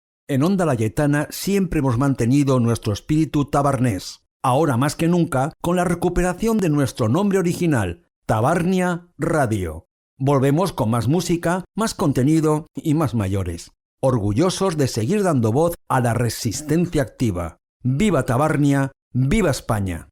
Anunci del retorn de la ràdio